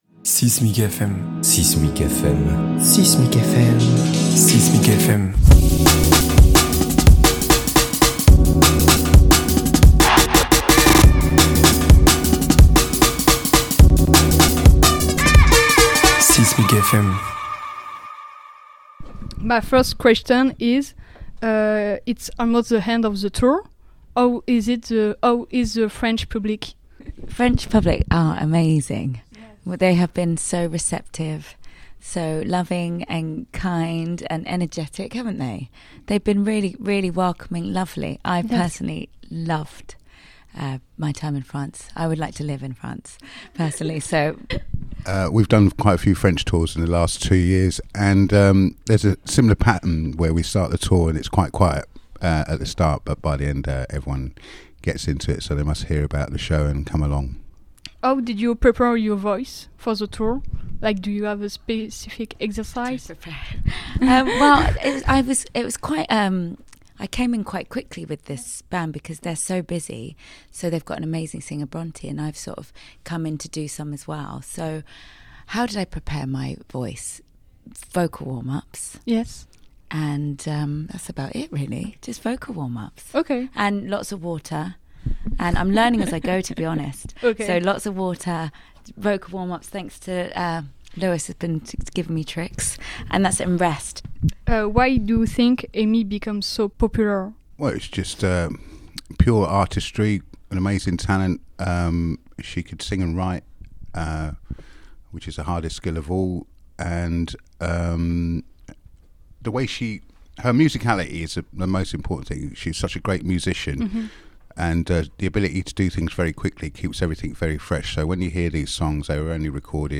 6mic FM est une nouvelle collaboration entre 6mic et RadioZai où les musiciens discutent avec nous dans ce lieu emblématique d'Aix-en-Provence.